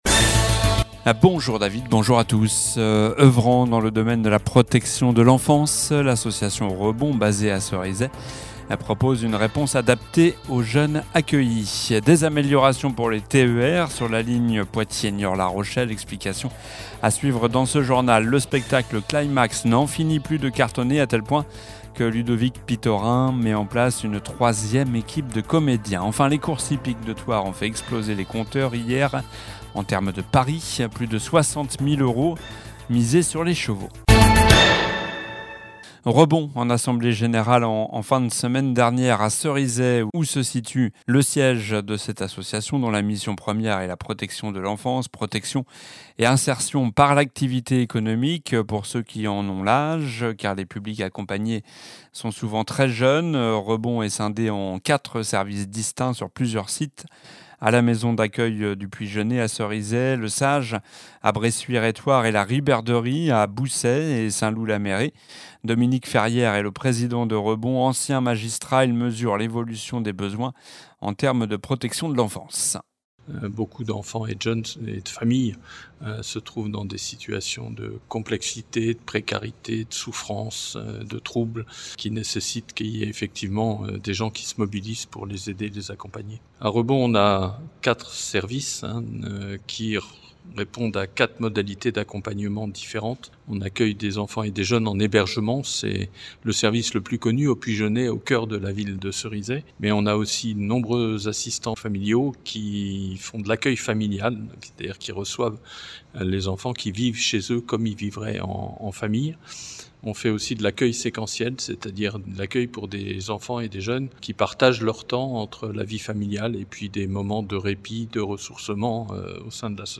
Journal du mardi 10 juin (midi)